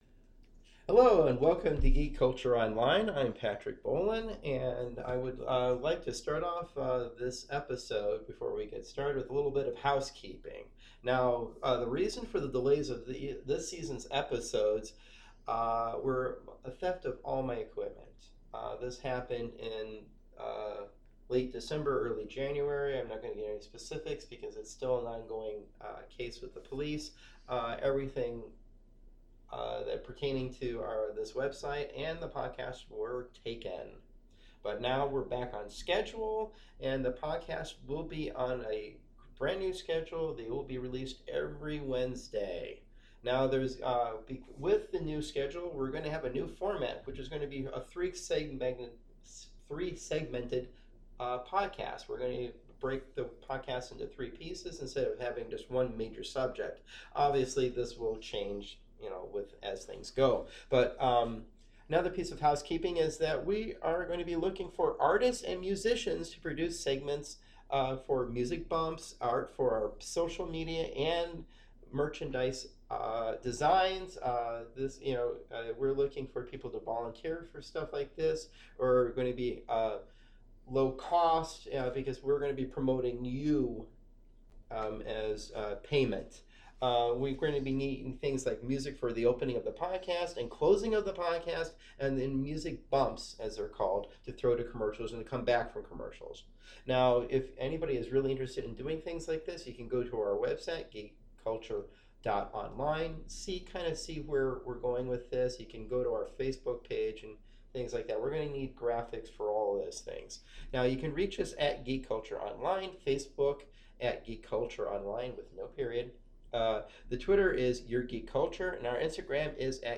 We talk about an awesome comic book, Archer & Armstrong, we talk about Spies with “Man From U.N.C.L.E” movie and finally about the X-Files. Sit back and make a drinking game with all my ‘Uhms’ I say.